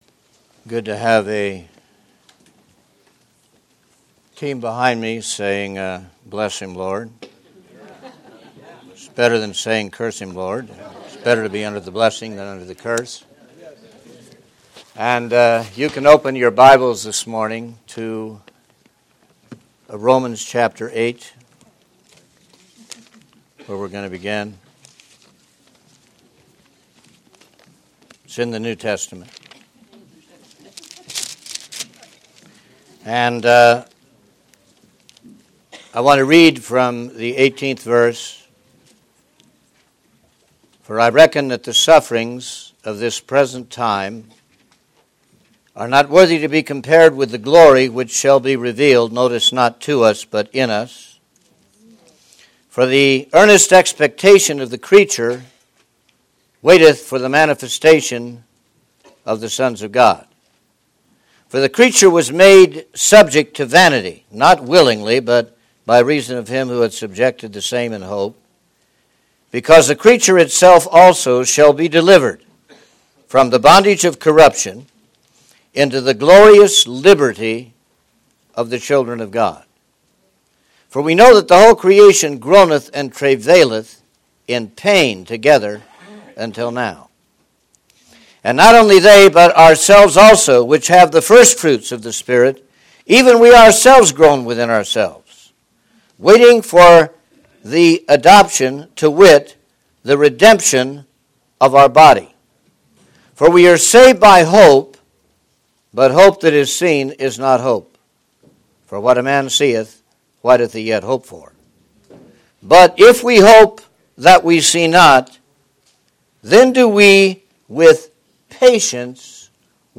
2015 Shepherds Christian Centre Convention